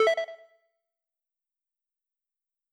tetromino_rotate.wav